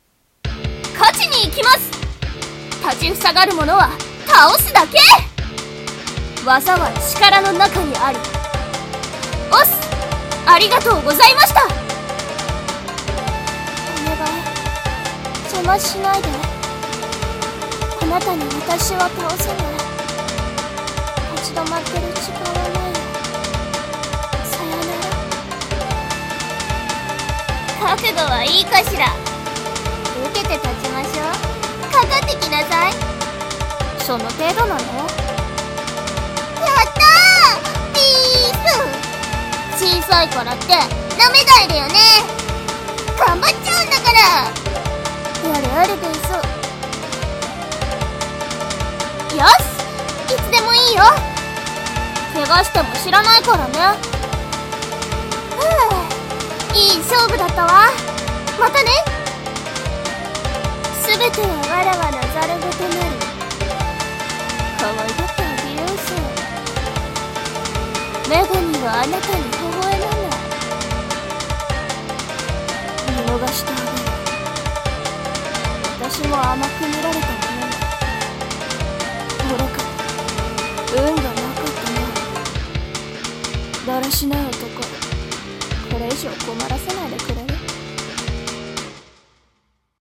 【演じ分け】『格闘ゲーム風台詞集】女ver.